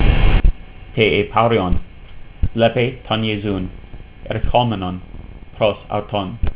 You can click on the verse to hear me read it.